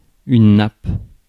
Ääntäminen
US : IPA : [ˈleɪ.ɚ] UK : IPA : /ˈleɪ.ə/